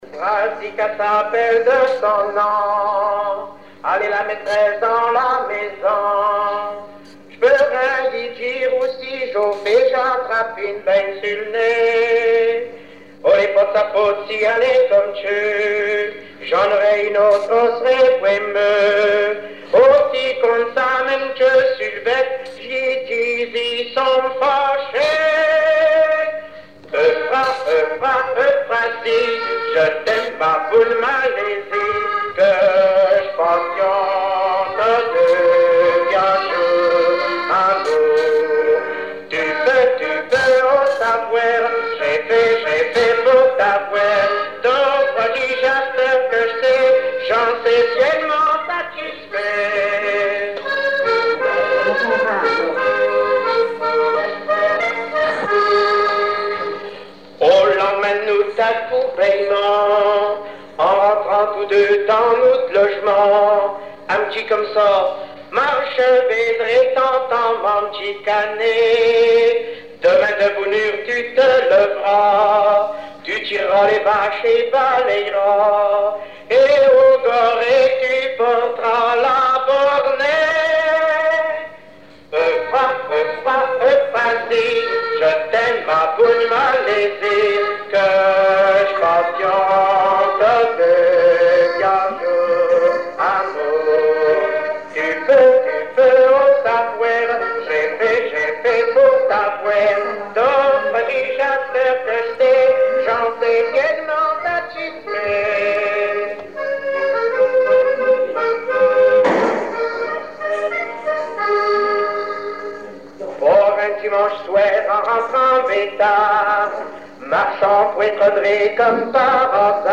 extrait d'une émission
chanson locales et traditionnelles
Pièce musicale inédite